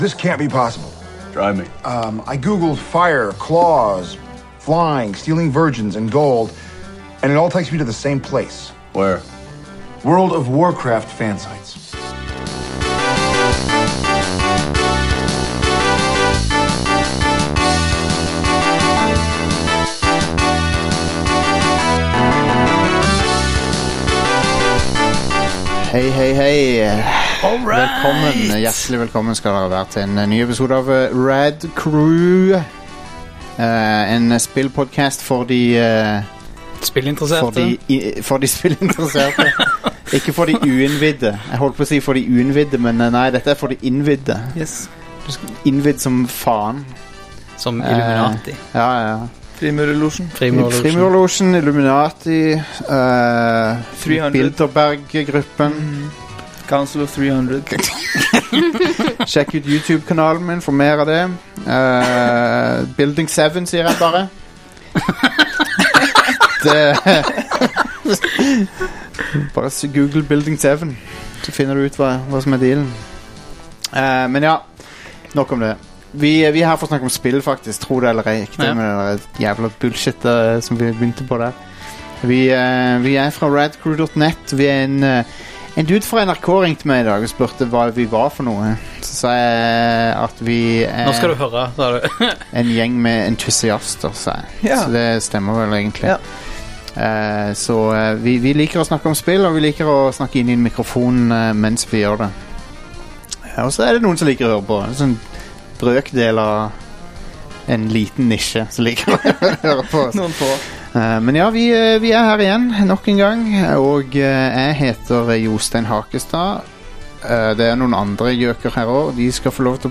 Podkaster om spill, popkultur, film og TV siden 2011